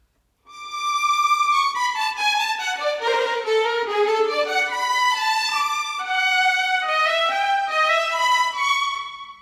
Modern 26 Violin 01.wav